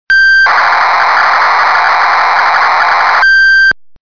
A fairly basic analog synth with memory and bbd chorus/ensemble/phaser.